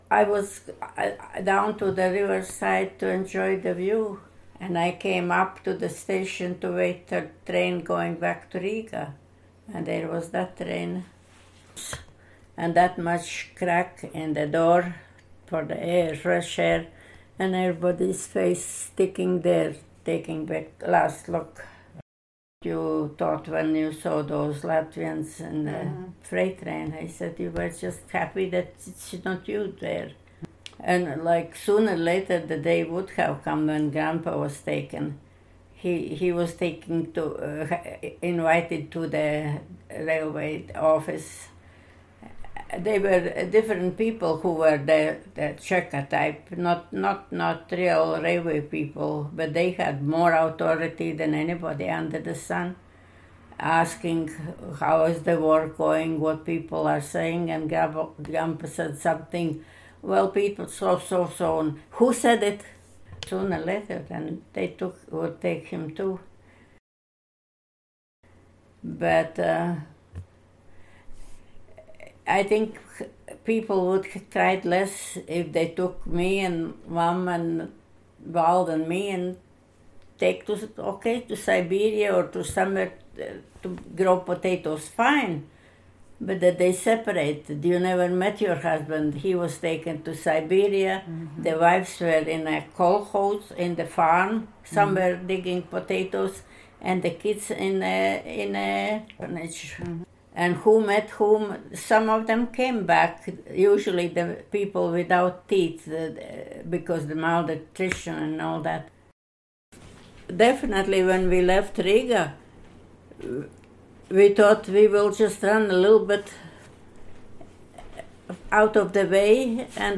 Interviewer (ivr)